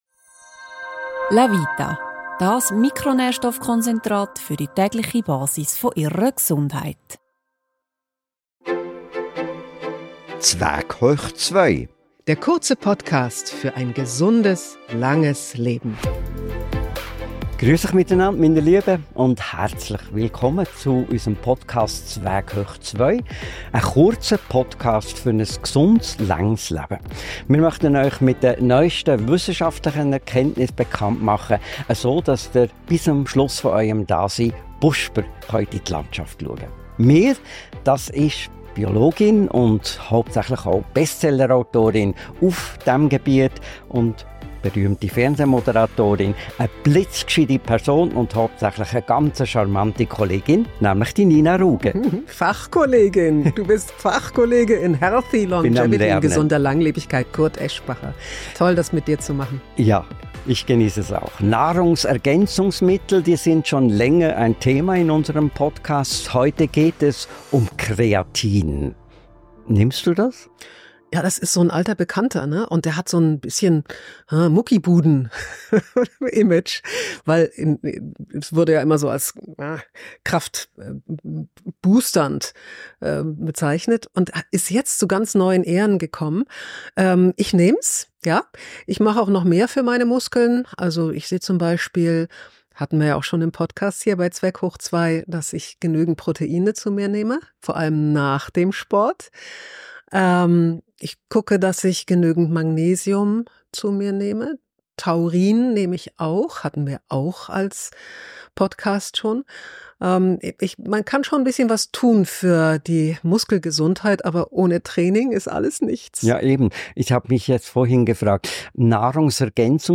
Kreatin unterstützt nicht nur Muskeln, sondern auch Gehirn und Herz. Eine tägliche Einnahme von bis zu 5 Gramm wird für Erwachsene empfohlen, besonders in Kombination mit Sport. Darüber sprechen Nina Ruge und Kurt Aeschbacher in dieser Podcast-Folge.